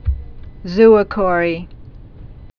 (zōə-kôrē)